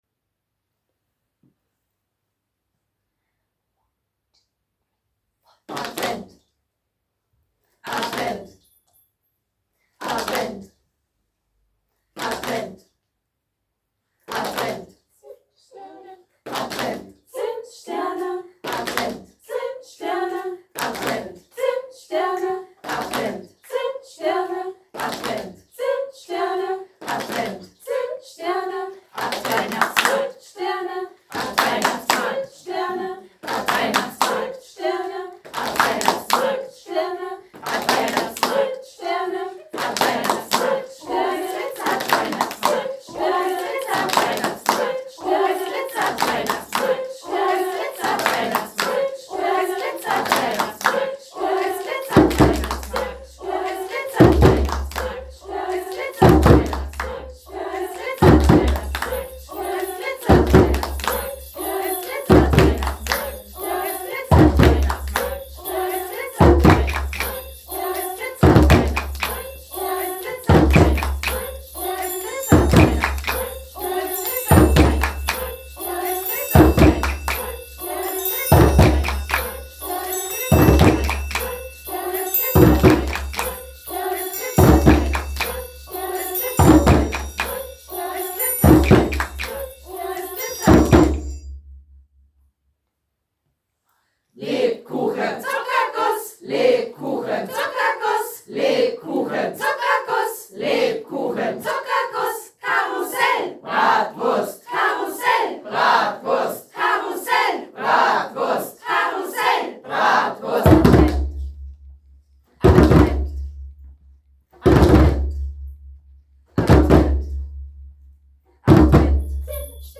ADVENT-rhythmical-BLKM.mp3